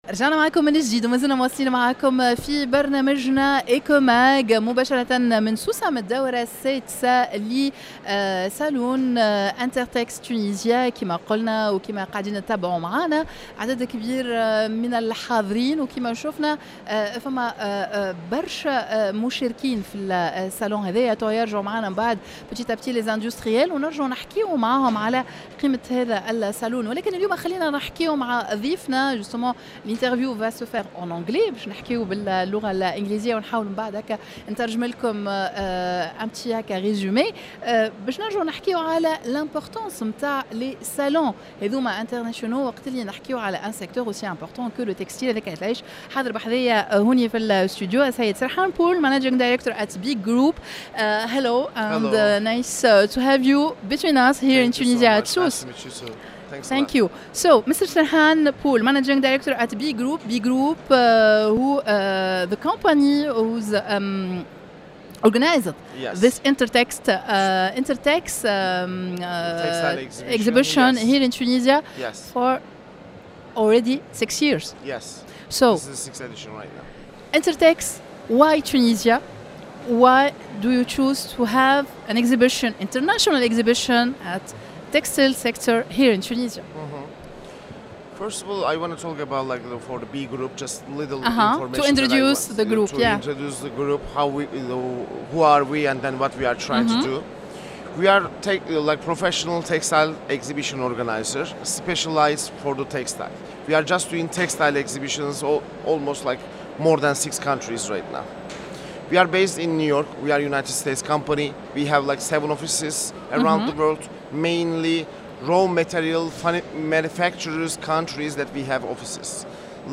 dans un plateau spécial en direct de la foire internationale de Sousse.